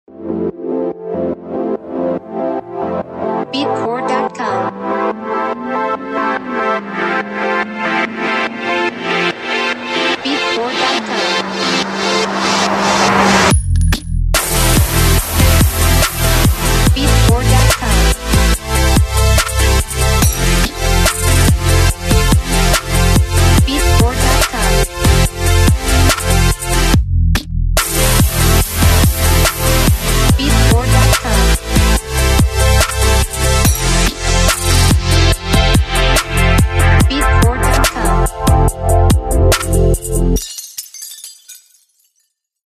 Instruments: Synthesizer